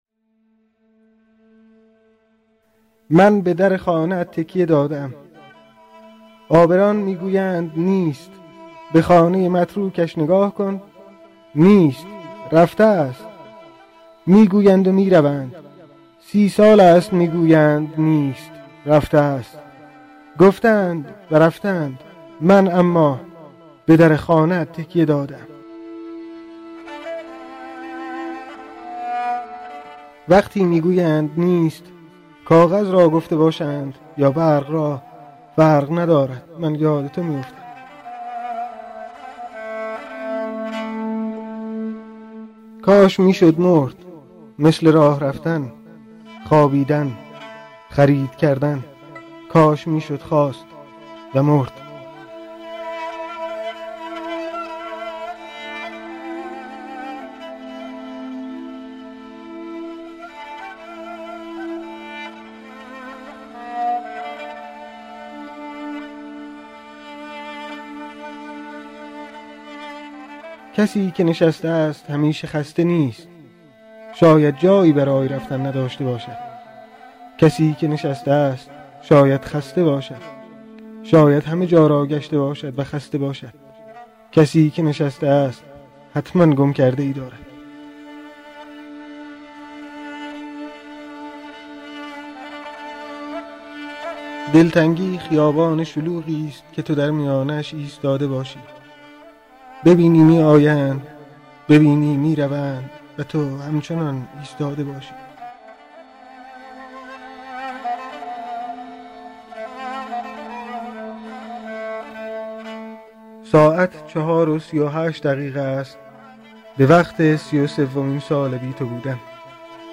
شعرخوانی علیرضا روشن